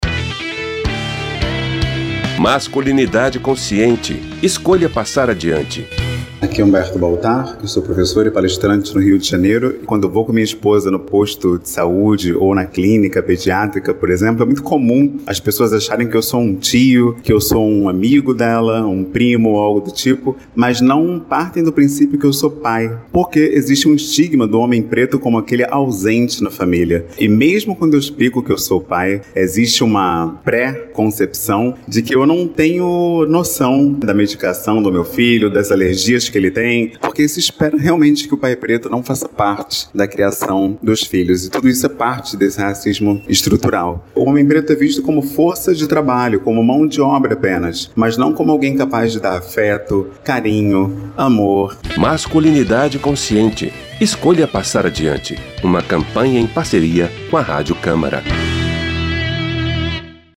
Em 13 depoimentos em áudio e cinco em vídeo com histórias reais de homens que romperam com medos e crenças a respeito da masculinidade, a Rádio Câmara lança a Campanha Masculinidade Consciente – escolha passar adiante.